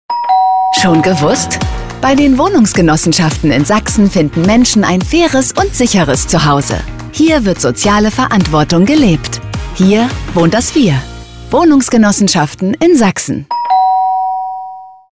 Spot "Hier wohnt das WIR"